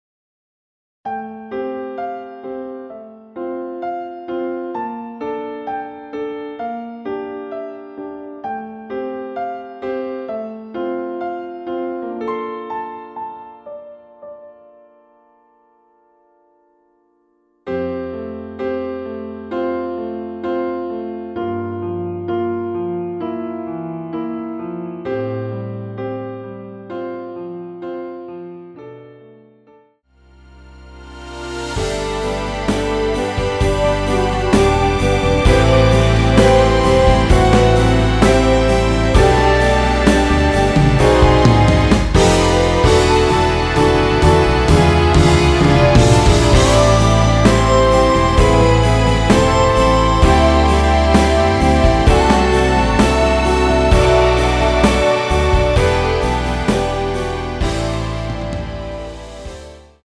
대부분의 여성분이 부르실수 있는 키로 제작 하였습니다.(미리듣기 참조)
앞부분30초, 뒷부분30초씩 편집해서 올려 드리고 있습니다.
중간에 음이 끈어지고 다시 나오는 이유는